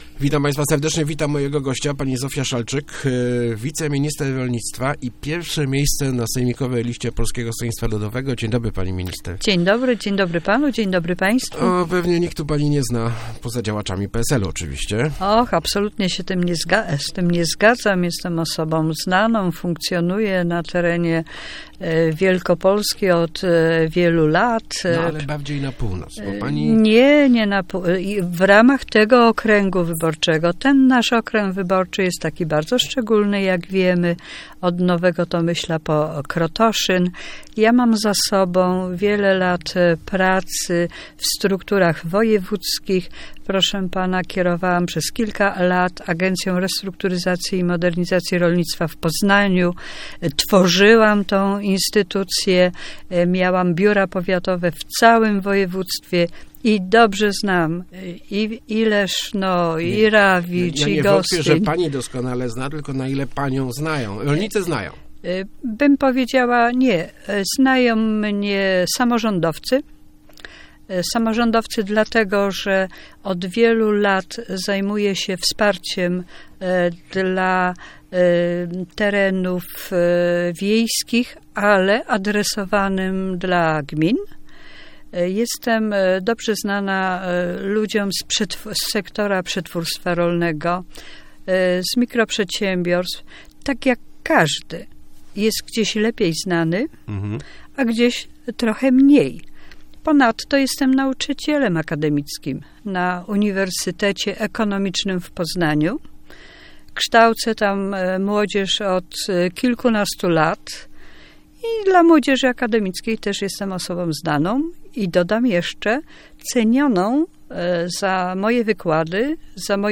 Widzę różnicę w traktowaniu różnych regionów Wielkopolski przez władze województwa - mówiła w Rozmowach Elki Zofia Szalczyk, wiceminister rolnictwa, liderka listy PSL do Sejmiku.